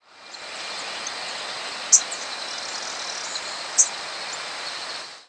Common Yellowthroat diurnal flight calls
Perched bird.